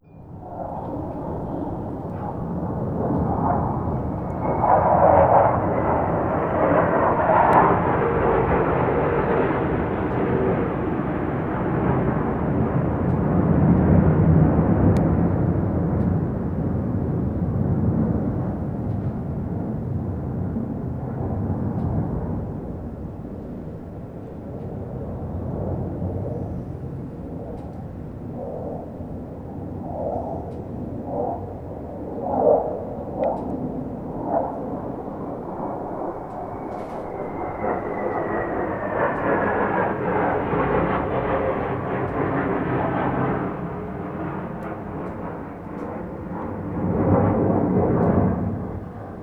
• F16 Fighter Jet passing by.wav
Recorded with a Tascam DR40 while few F 16 Fighters fly over our small apartment building.
F16_Fighter_Jet_passing_by_Vn5.wav